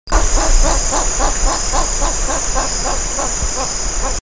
Nervous birds flick the tail slowly (see FPAVE128VI), before flushing to a low tree giving a croaking call.
Tigrisomalineatumflush (2).wav